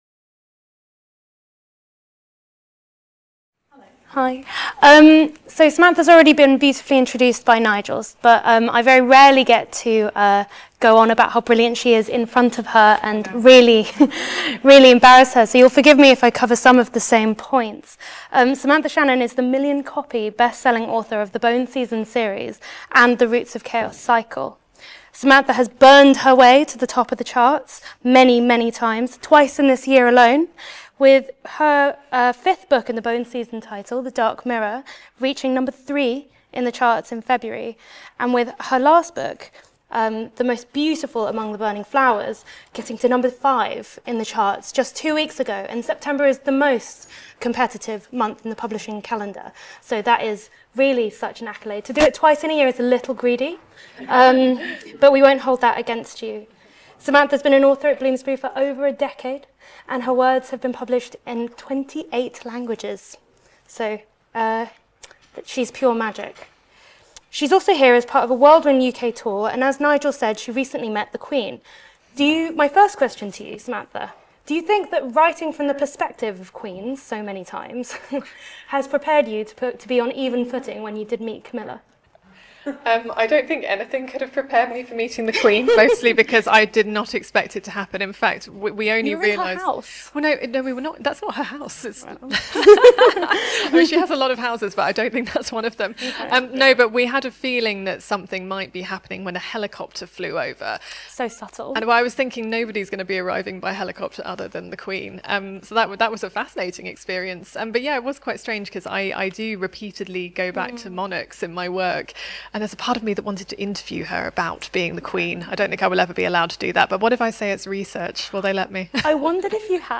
Interview with Samantha Shannon
Part of the Bloomsbury-Oxford Summer School (23rd-25th September 2025) held at Exeter College.